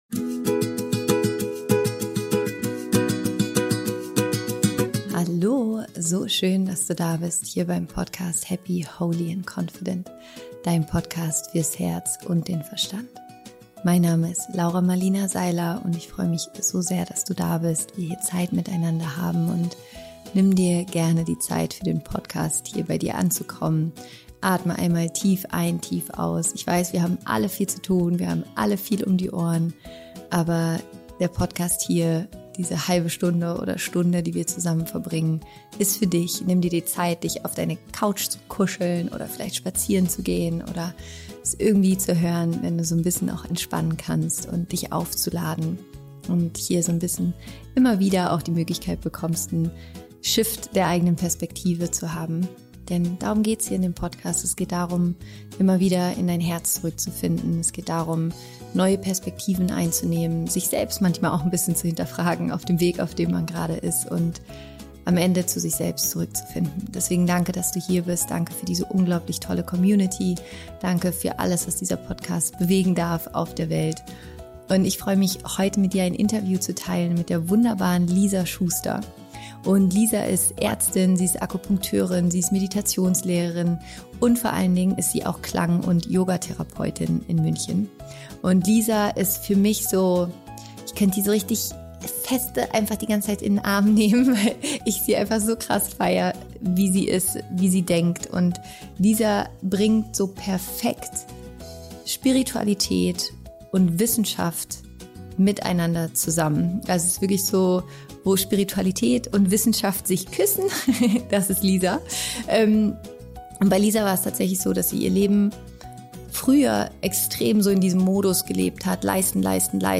happy, holy & confident® Dein Podcast fürs Herz und den Verstand
Am Ende der Folge erwartet dich eine ganz wundervolle und heilsame Sound Bath-Meditation.